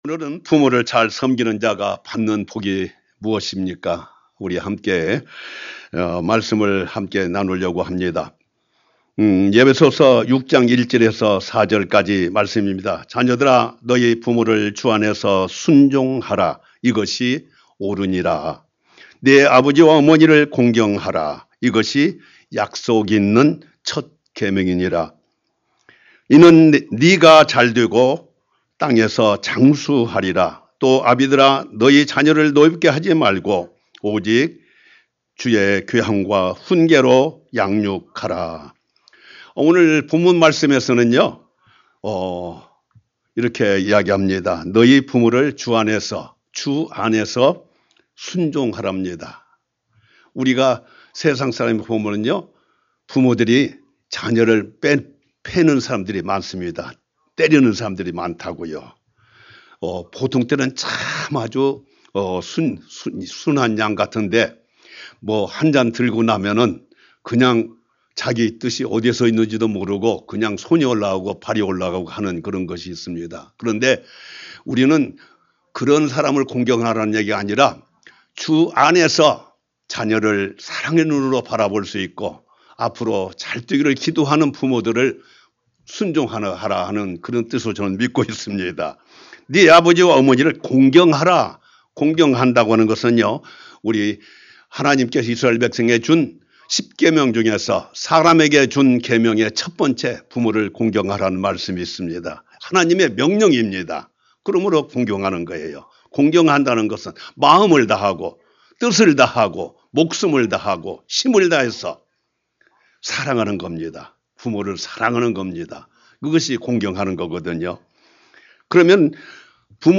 Sermon - 부모를 잘 섬기는 자가 받을 복 The blessings of honoring your parents well.